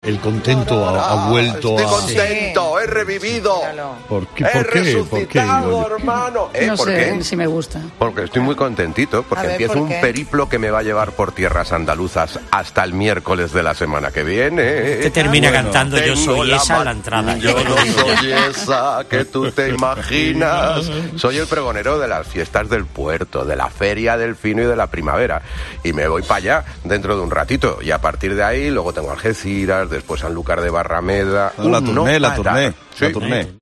Así anunciaba, Goyo González, su llegada hoy a El Puerto de Santa María